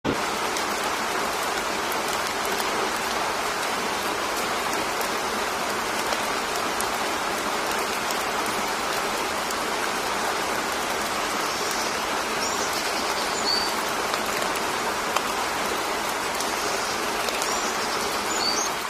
Rain.mp3